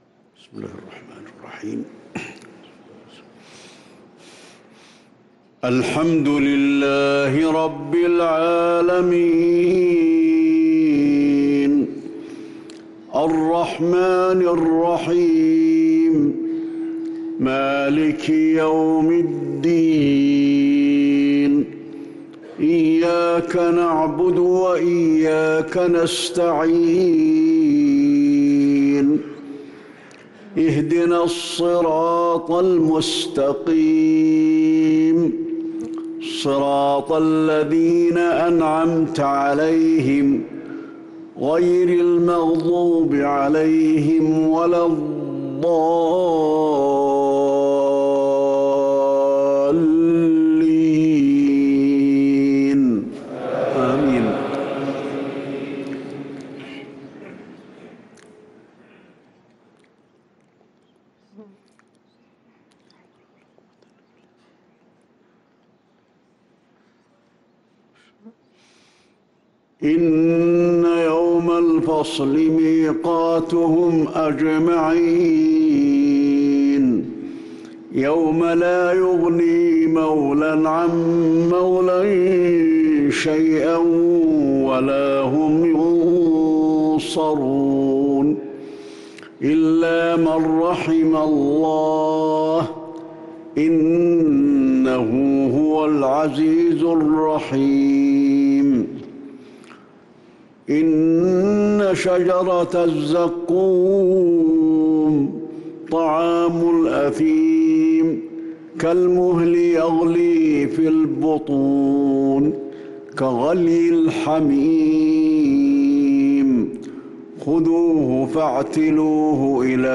صلاة المغرب للقارئ علي الحذيفي 11 رمضان 1444 هـ
تِلَاوَات الْحَرَمَيْن .